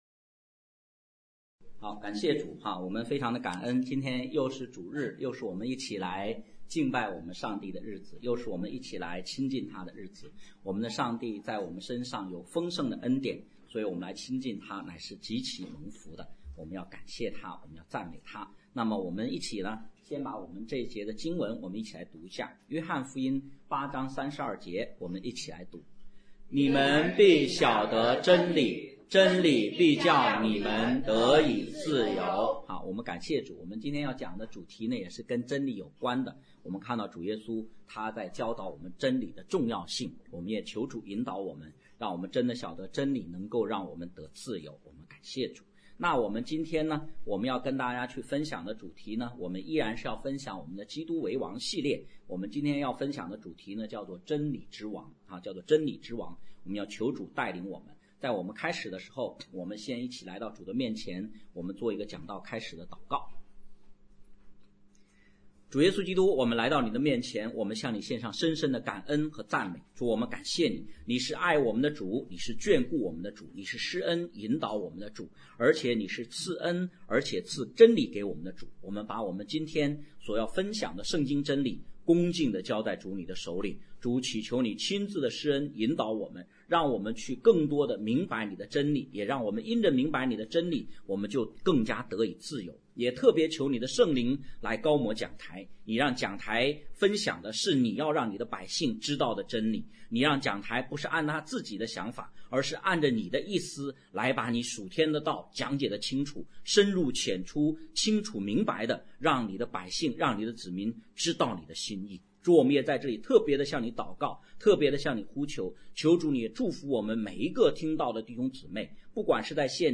讲道录音 点击音频媒体前面的小三角“►”就可以播放： https